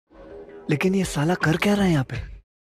lekin ye sala Meme Sound Effect